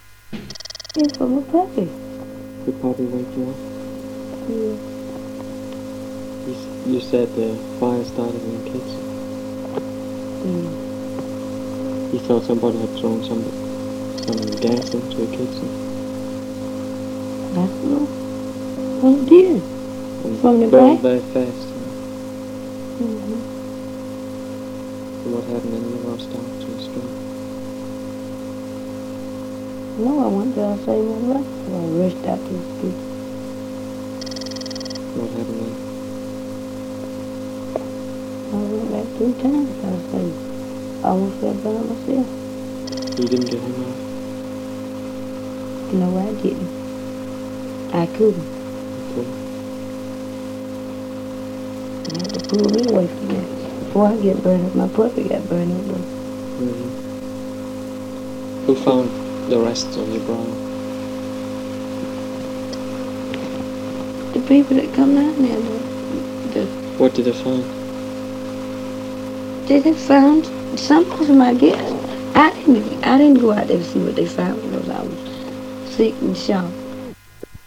Interview 3 month after fire